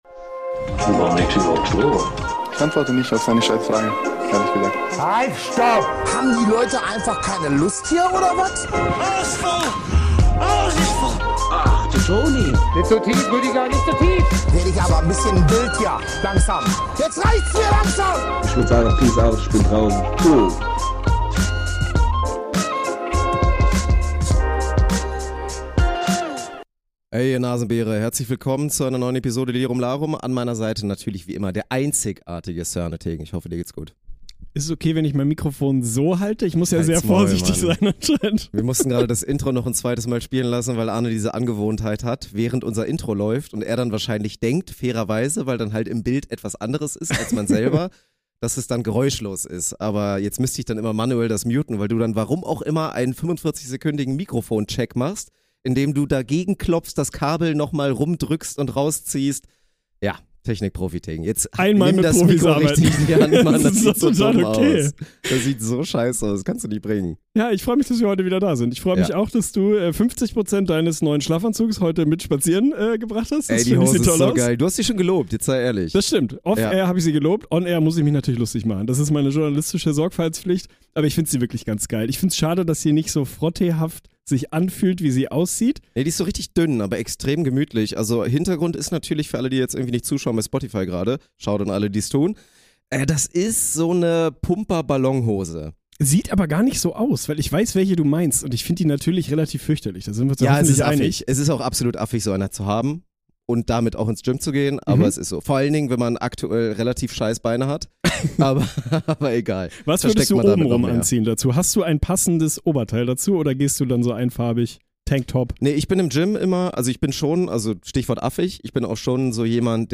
Von Million Dollar Ideas, Pimmelwitzen und Kneipen-Talk bis zu den ganz großen Fragen des Lebens. Mal tief, mal flach, mal laut, mal leise.